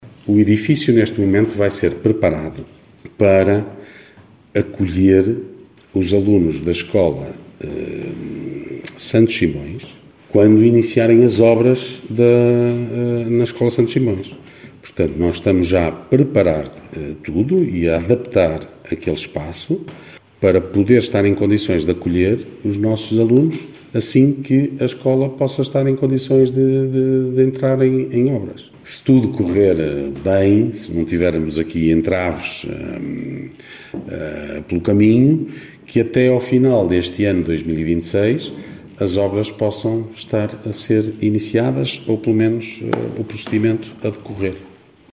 Declarações de Ricardo Araújo, presidente da Câmara Municipal de Guimarães. Falava esta segunda-feira, à margem da reunião do executivo.